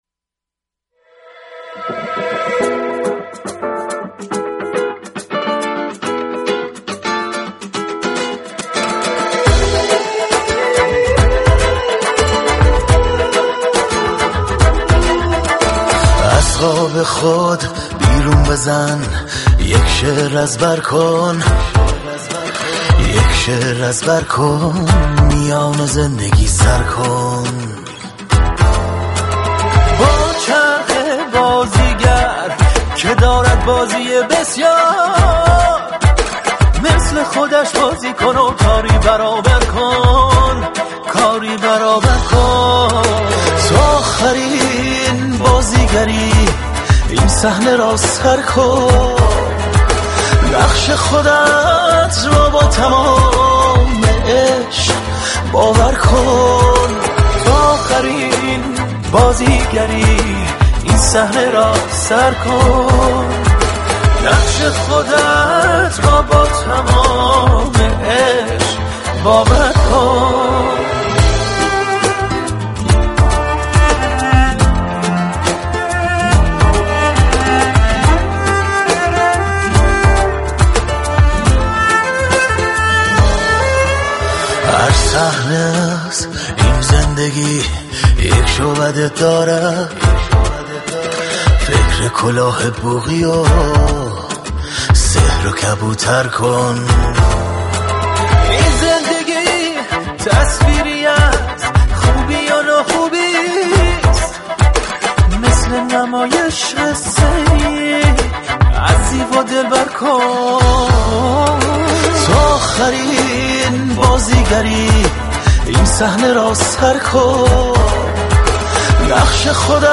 كاخن
گیتار
كمانچه
تلفیق آن با موسیقی كلاسیك و پاپ